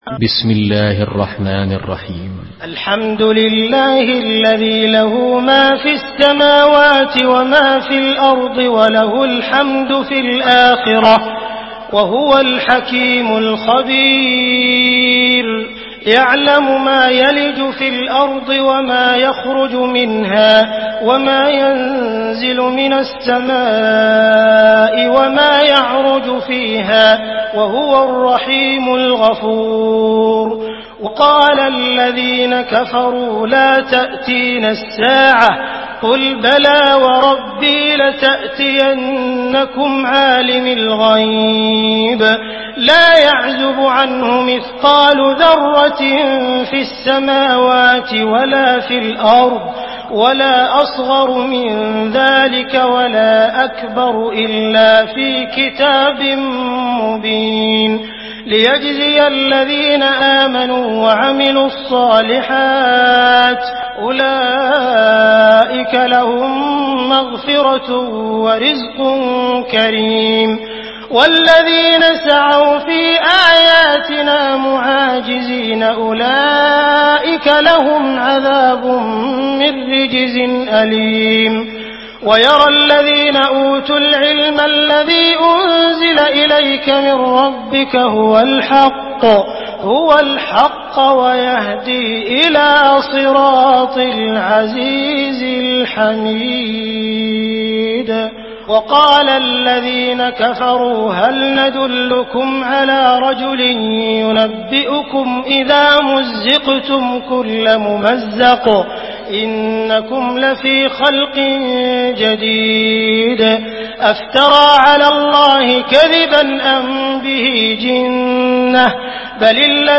سورة سبأ MP3 بصوت عبد الرحمن السديس برواية حفص
مرتل